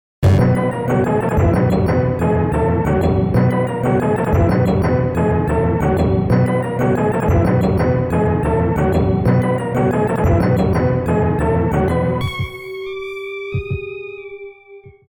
※一部の楽曲に収録の都合によりノイズが入る箇所があります。